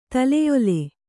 ♪ taleyole